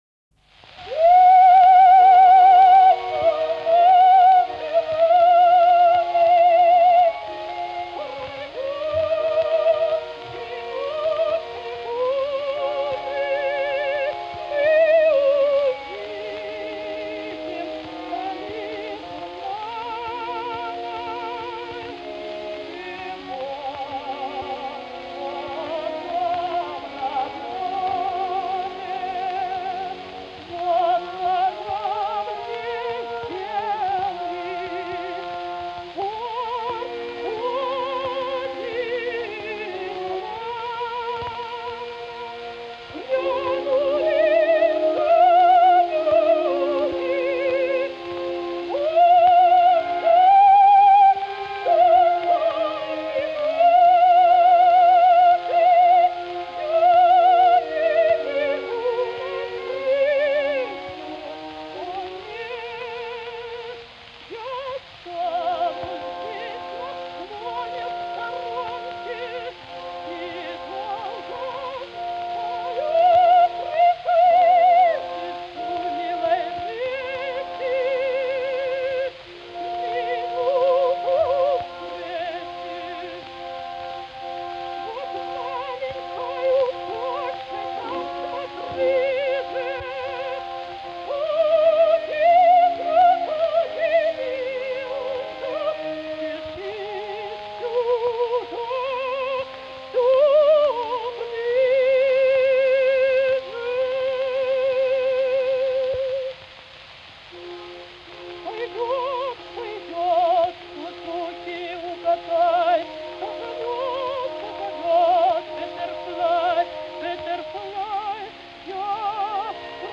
Ермо́ленко-Ю́жина Ната́лья Степа́новна (урождённая Плуговская, сценический псевдоним Ермоленко, в замужестве Южина) (1881, Киев – 1937, Париж), российская певица (драматическое сопрано), заслуженная артистка Республики (1920).
Наталья Ермоленко-Южина в партии Лизы в опере «Пиковая дама» П. И. ЧайковскогоНаталья Ермоленко-Южина в партии Лизы в опере «Пиковая дама» П. И. Чайковского. 1911.Наталья Ермоленко-Южина в партии Лизы в опере «Пиковая дама» П. И. Чайковского. 1911.Обладала исключительным по силе и красоте «сочным» драматическим сопрано широкого диапазона, позволявшим ей исполнять также партии для меццо-сопрано (Кармен в , Марина Мнишек в опере «Борис Годунов» Мусоргского).
Записывалась на грампластинки в 1903–1912 гг. в Петербурге и Москве (фирмы «В. И. Ребиков», «Пате», «Граммофон»).